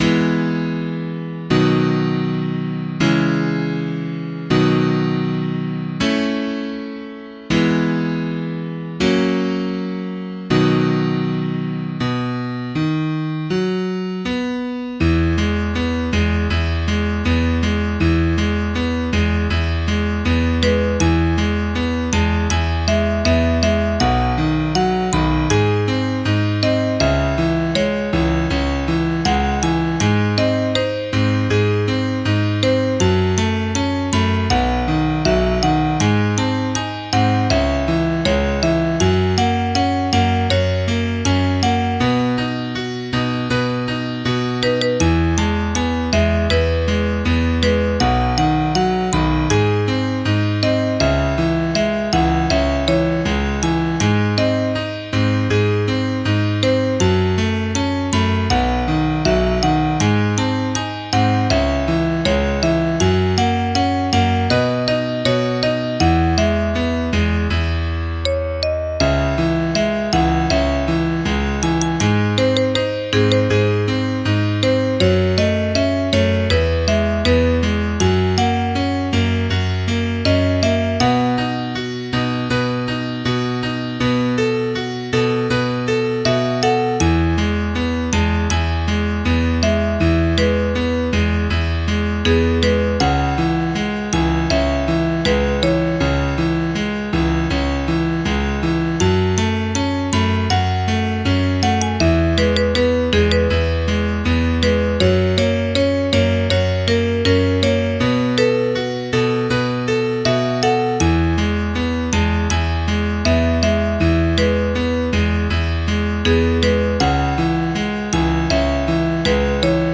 MIDI 17.47 KB MP3 (Converted) 5.11 MB MIDI-XML Sheet Music